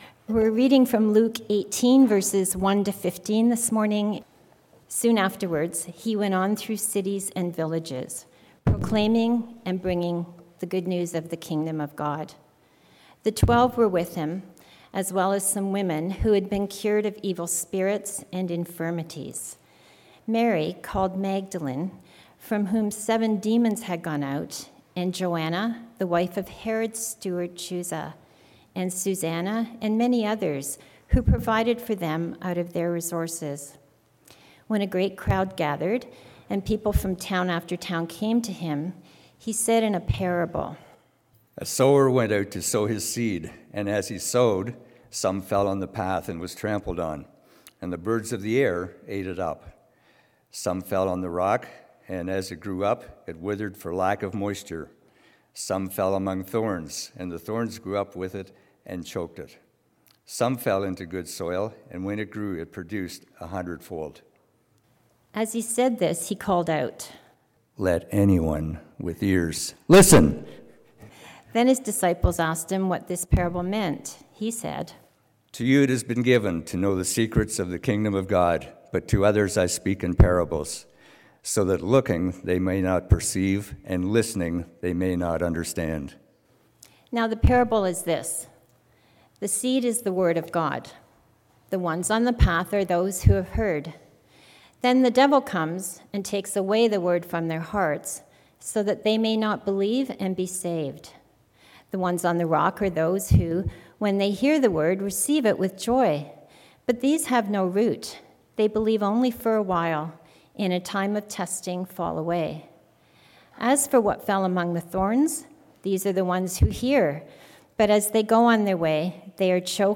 Sermons | Weston Park Baptist Church
sermon_oct28.mp3